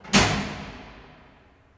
Heavy Switch (2).wav